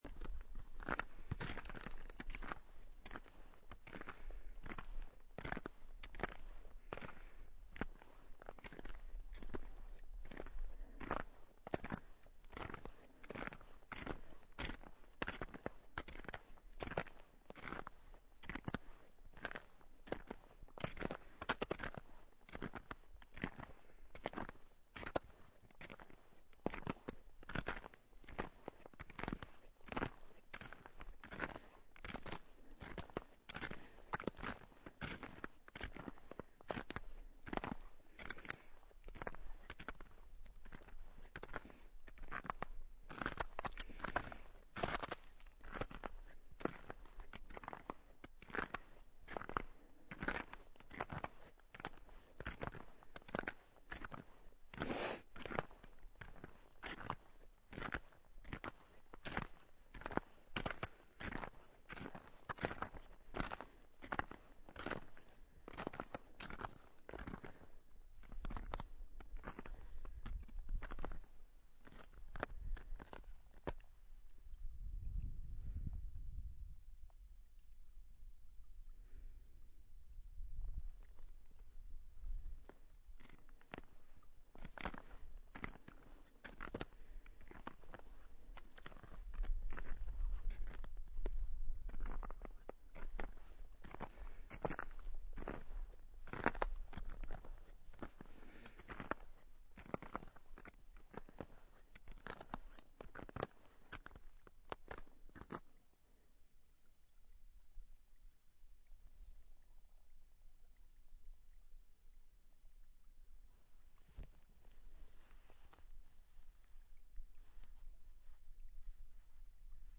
Otherworldly sound of Taylor Glacier
I made this binaural recording while walking on Taylor glacier.
Listen especially to the strange, almost electronic sound that occurs in the last minute. It is coming from moving water under the surface of the glacier.
walking_on_taylor_glacier_w_end.mp3